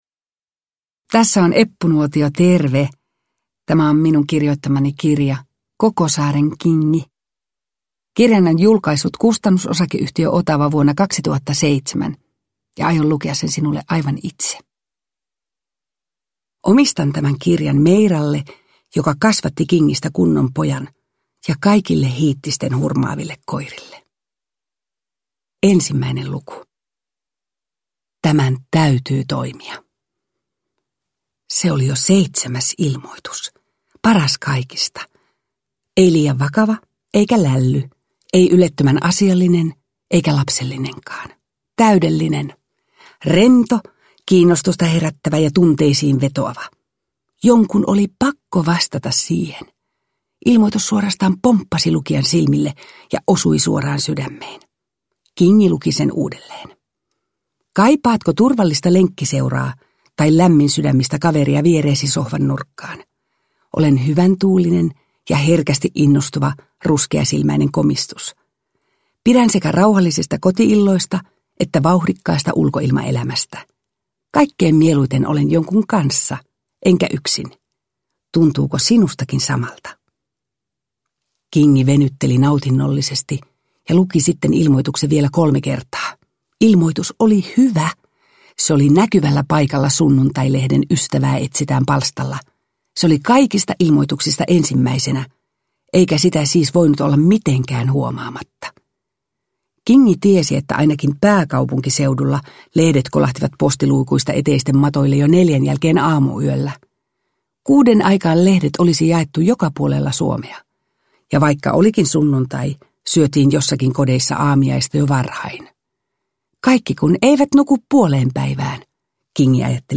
Koko saaren Kingi – Ljudbok – Laddas ner
Uppläsare: Eppu Nuotio